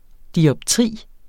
Udtale [ diʌbˈtʁiˀ ]